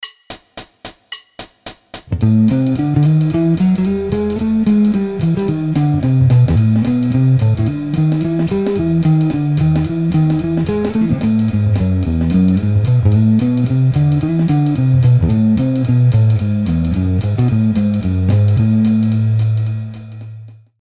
lsnwalkingbass.mp3